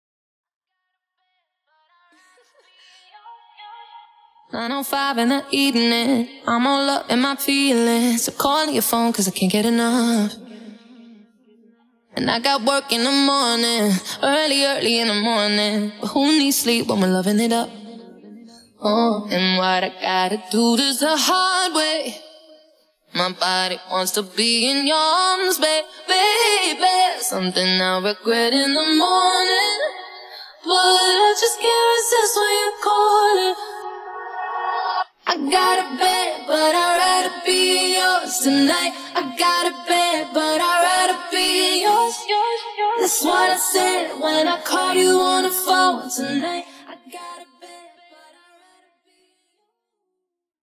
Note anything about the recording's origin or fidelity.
DEMO DRY VERSION :